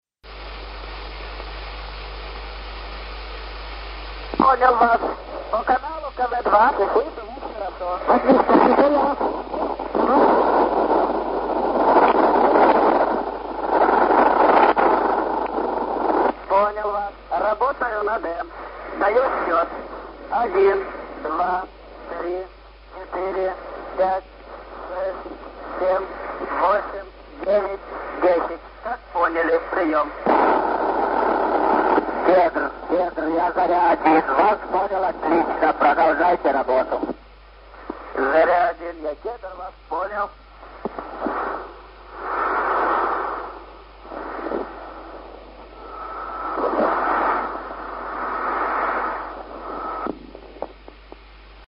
Ю.А. Гагарин. Запись переговоров между космическим кораблем Восток-1 и Центром управления полетом.